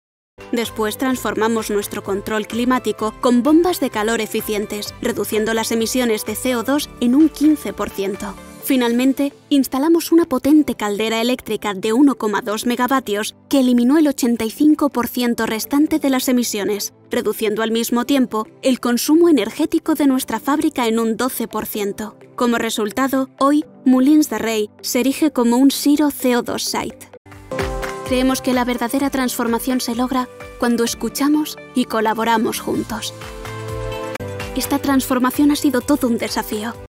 une voix off espagnole lumineuse et captivante – aiguë, vivante et polyvalente – idéale pour les publicités, l'e-learning et la narration.
Vidéos explicatives
Microphone : Neumann TLM 102.